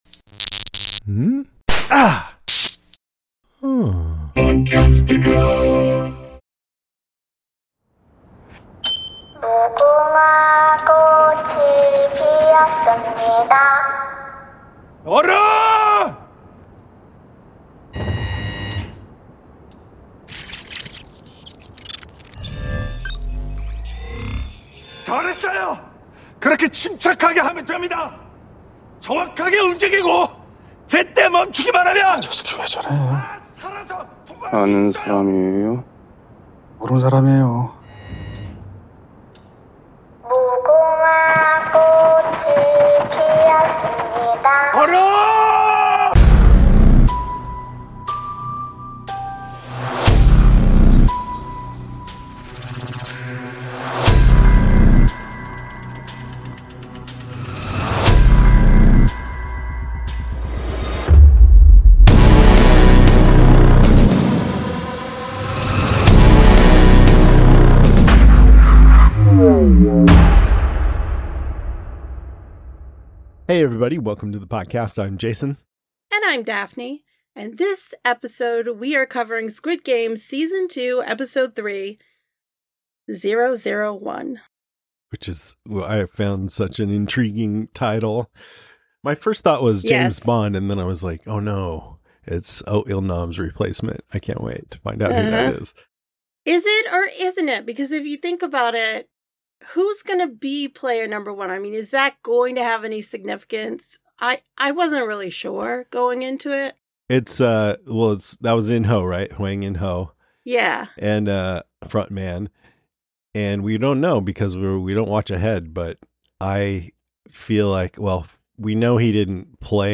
We have in-depth, fun conversations about TV shows like Squid Game, Cobra Kai, Yellowjackets, The White Lotus, House of the Dragon, The Rings of Power, The Handmaid’s Tale, Andor, Dead to Me, and many more.